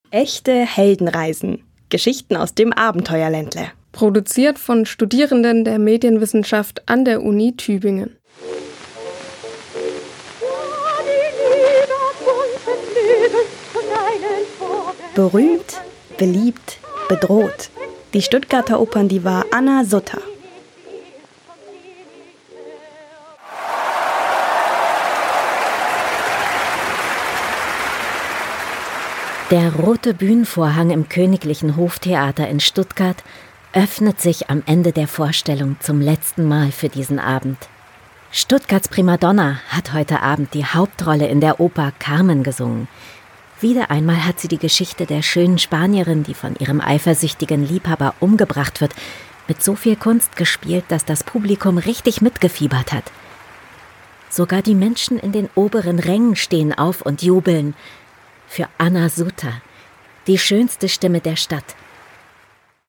Bei den Aufnahmen wurden sie von professionellen Sprecher:innen unterstützt.